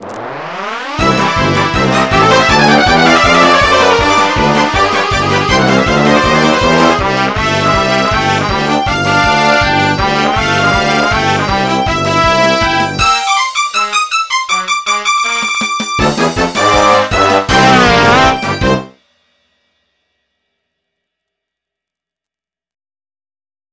MIDI 13.64 KB MP3